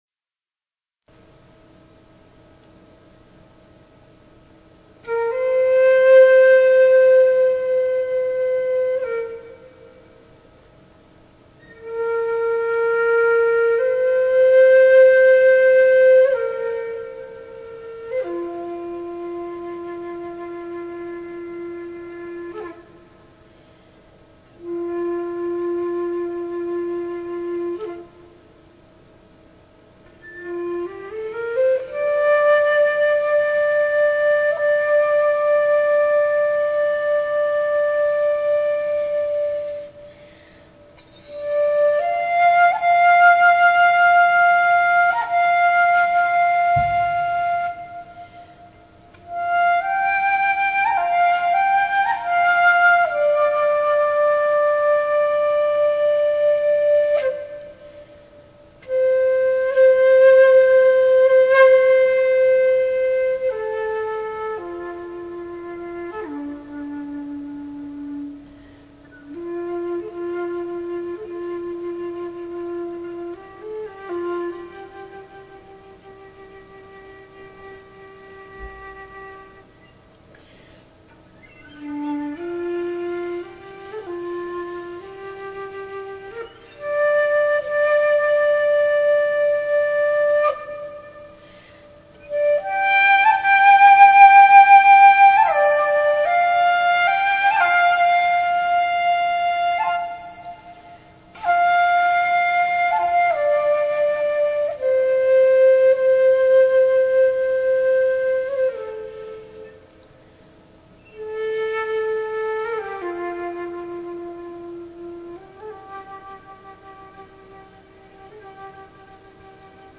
Talk Show Episode
The Elohim share how nodes of energy/frequency created space and time where none existed previously. As they communicated this information they connected up with the energies of listeners and at the end of the show, people had the opportunity to make silent requests.